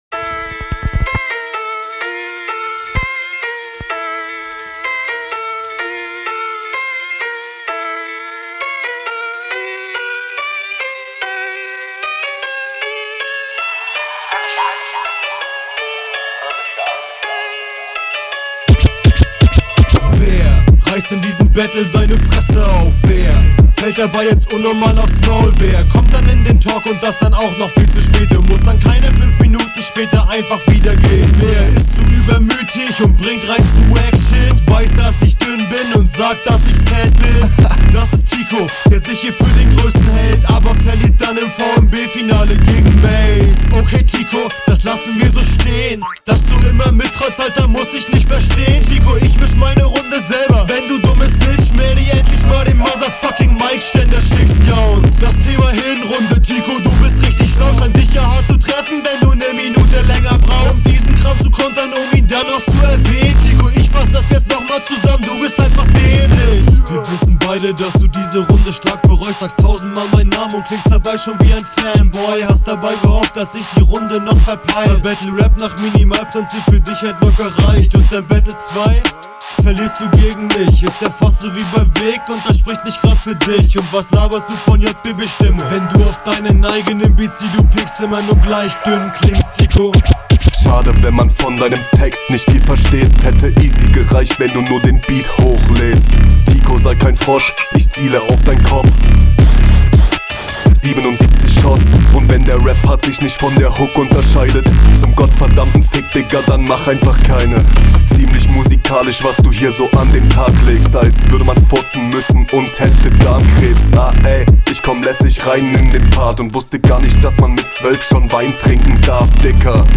Also erstmal props dass dus selbst gemischt hast und ich muss sagen..